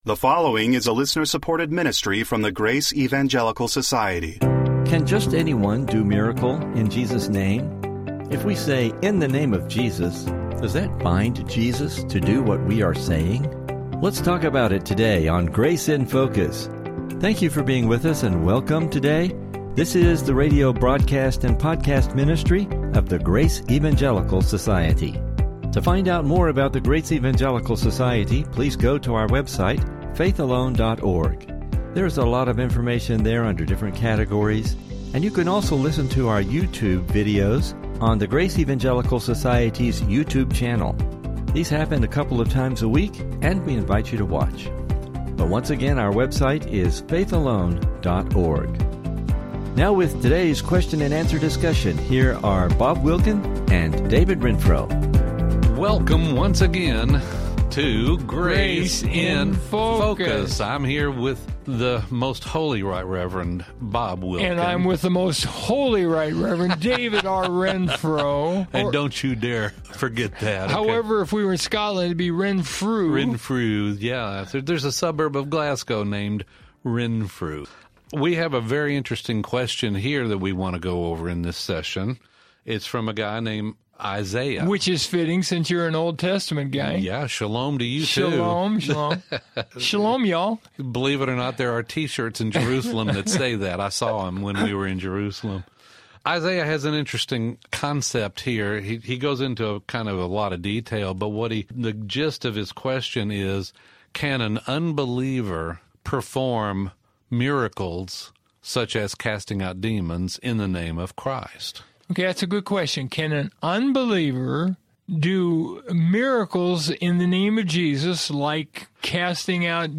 Welcome to the Grace in Focus radio.